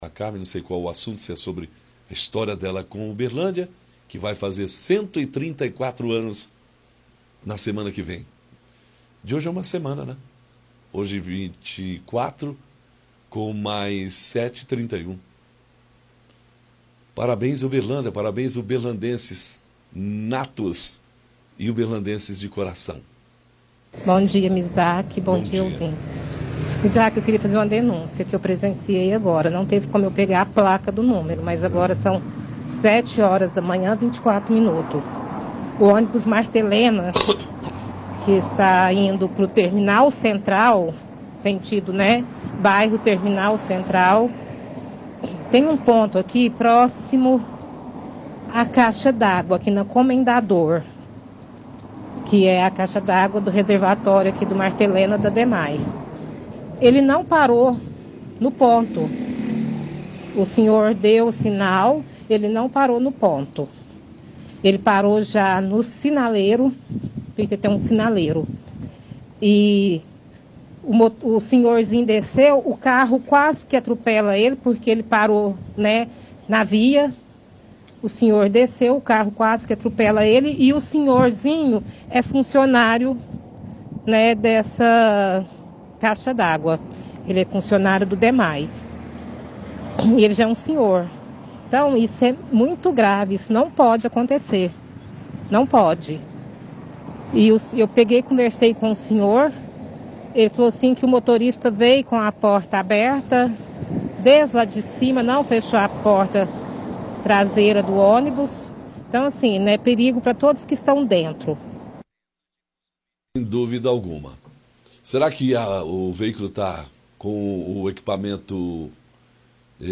Mulher relata que às 7:24 ônibus do Marta Helena indo pro Terminal Central, no ponto próximo à caixa d’água do Dmae na avenida Comendador Alexandrino, não parou no ponto, veio parar no semáforo para um idoso descer que quase foi atropelado por um carro que passava. O senhor disse também que o motorista estava andando com a porta aberta do ônibus já por algum tempo.